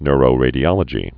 (nrō-rādē-ŏlə-jē, nyr-)